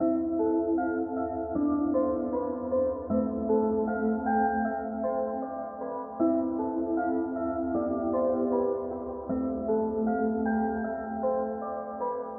冬天的心碎 Lil Peep类型的Loop
Tag: 120 bpm Trap Loops Guitar Electric Loops 2.69 MB wav Key : A FL Studio